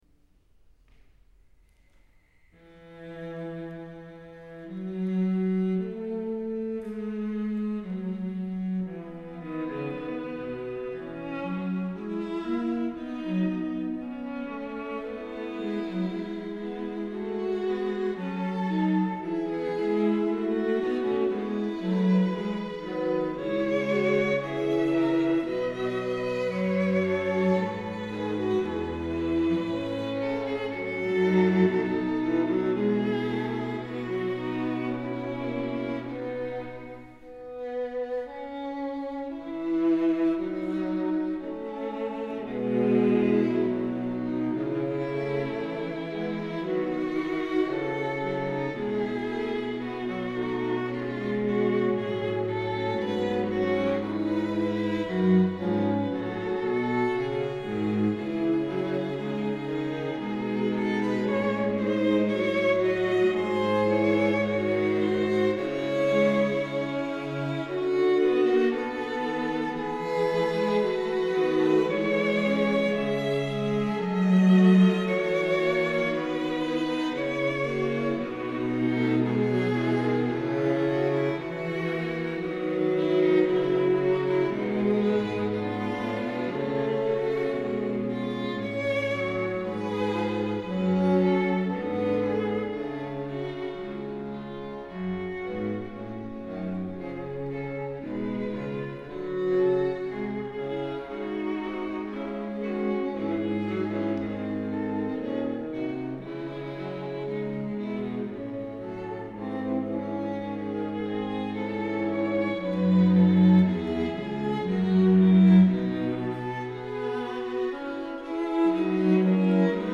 Wigmore Hall de Londres
Avui us proposo una fuga en Mi major del Clavecí ben temperat de J.S.Bach, la magna obra del geni de Leipzig, en un arranjament per a quartet de corda de W.A.Mozart, el geni de Salzurg.
La versió prové d’un concert celebrat abans d’ahir, dia 17 de novembre, al prestigiós Wigmore Hall de Londres, on el Emerson Quartet, format per Eugene Drucker (violí), Philip Setzer (violí), Lawrence Dutton (viola) i David Finckel (cello), varen oferir com a propina després de interpretar Shostakovitx i Schubert.
Versió curiosa d’un obra per piano o clavicèmbal, que si bé costa associar a la sonoritat d’un quartet de corda, no deixa de perdre ni un dels seus valors.
09-encore-bach-arrmozart-e-major-fugue.mp3